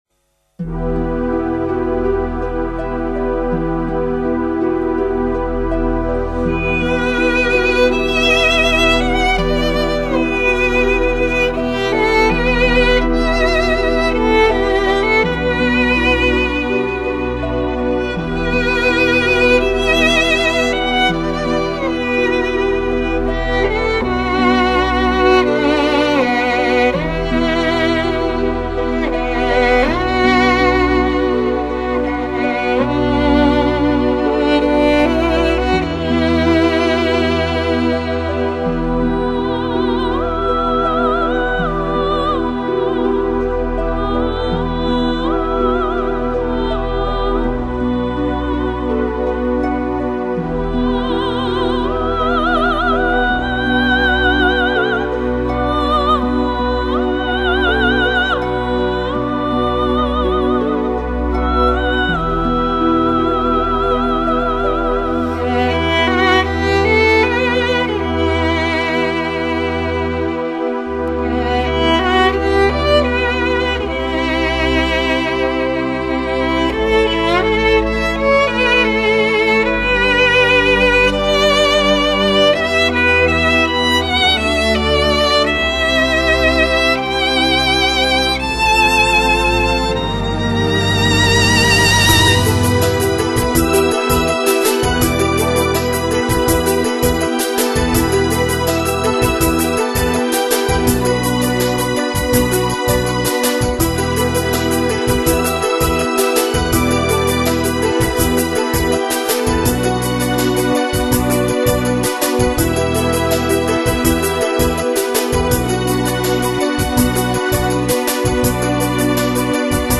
象梦一样，象山岚一般，若隐若现，似真亦幻，故事或许曾经非常的美好……
这是一张真正从编曲阶段就严格按照DVD-audio标准进行制作的DTS-CD。